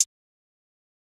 [05] Hi-Hats